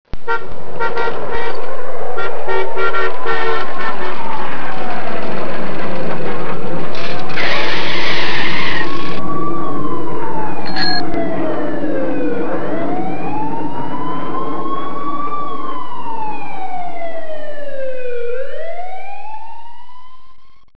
Effects 2